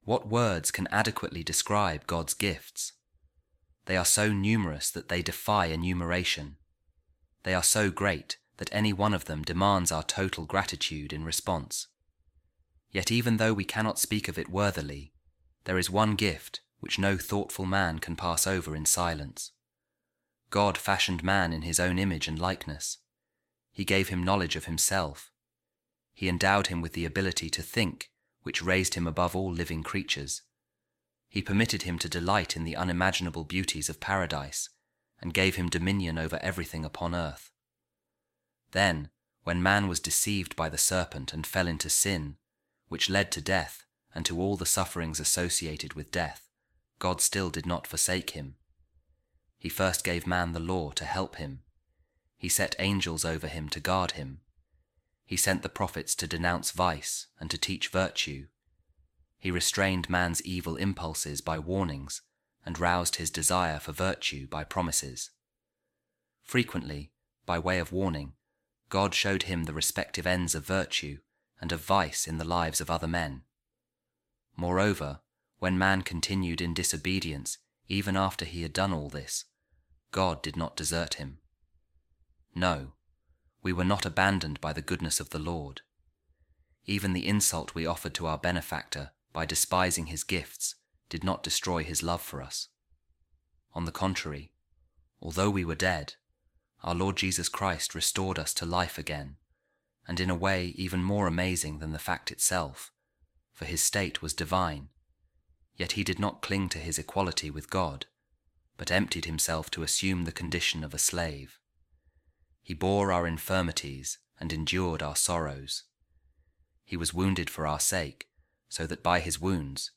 A Reading From The Longer Rules For Monks By Saint Basil The Great | How Shall We Repay The Lord For All His Goodness To Us?